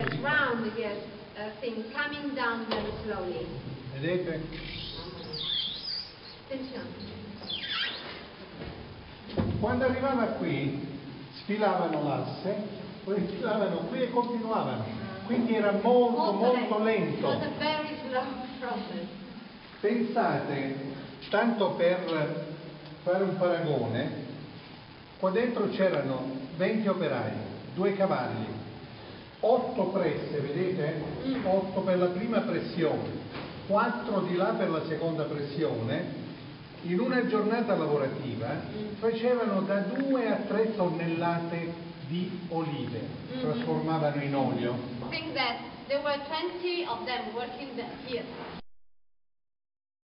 video clip of the mill owner showing how the press worked.